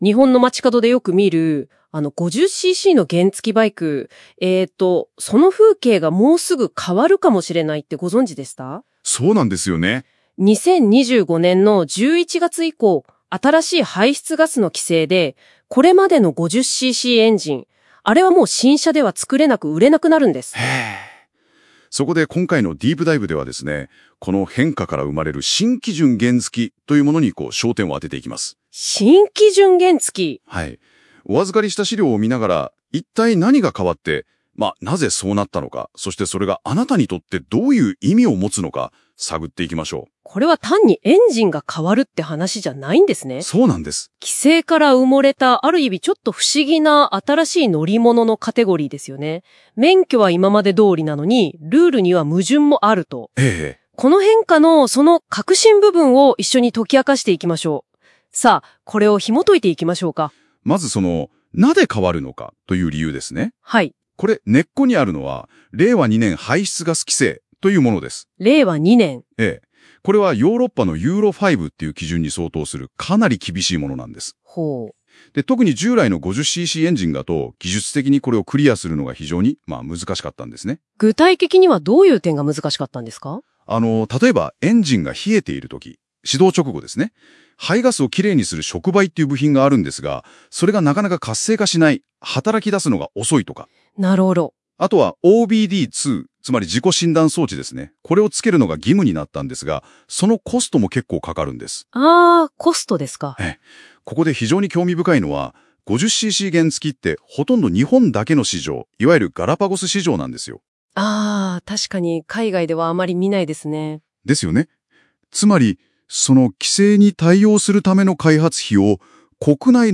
本記事の内容をAIで音声サマリーを作成しました。
※AIの漢字の読み間違いがあります。